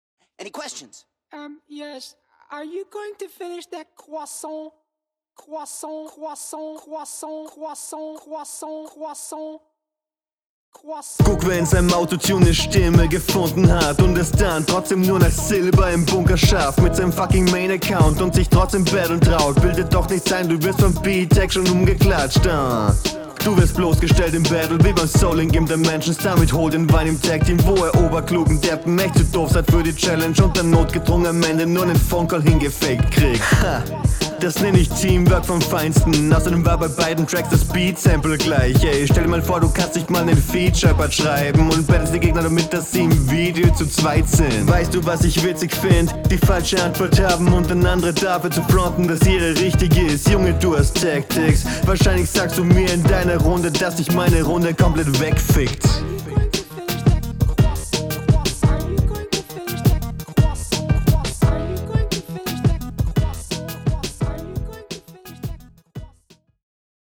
Der Beat ist genial.